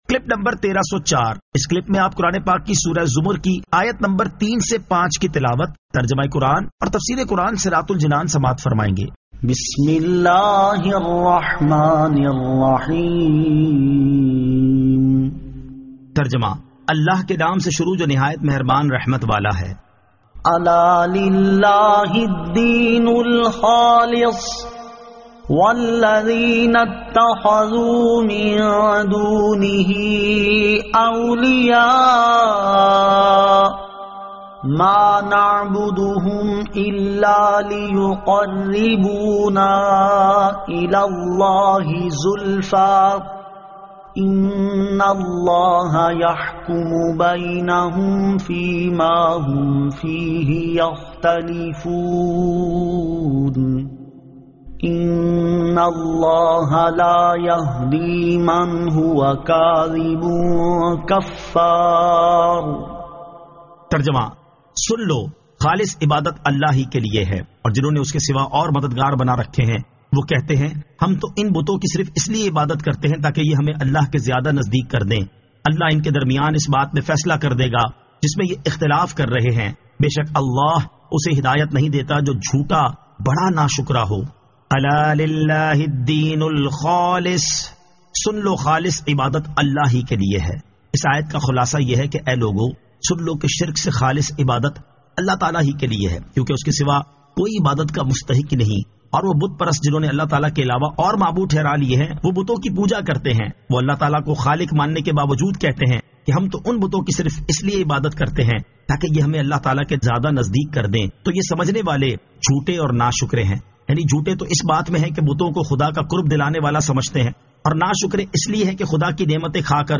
Surah Az-Zamar 03 To 05 Tilawat , Tarjama , Tafseer